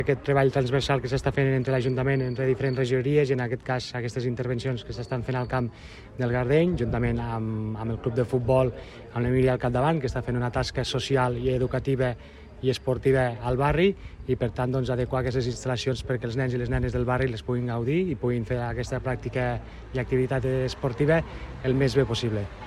Tall de veu del regidor Ignasi Amor sobre els treballs a les instal·lacions de la UE Gardeny per condicionar un gimnàs social, en el marc del projecte La Descomunal Lleida